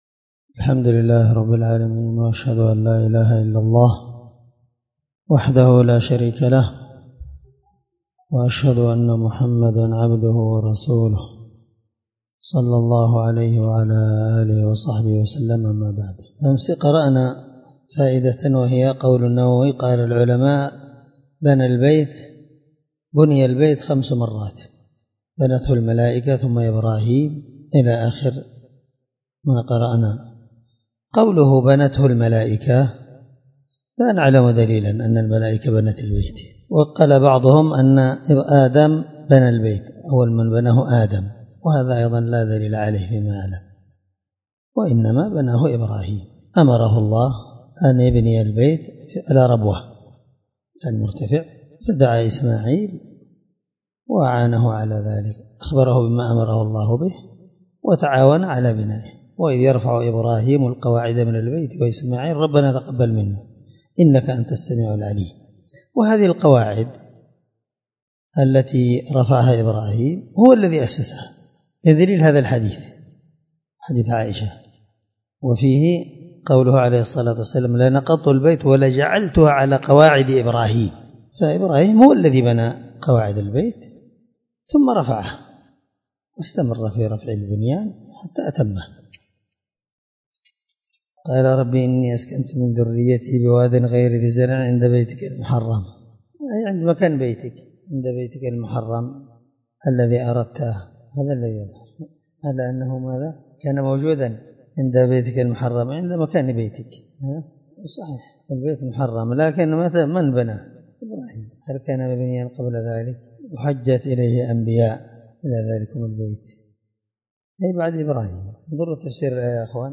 الدرس67من شرح كتاب الحج حديث رقم(0000) من صحيح مسلم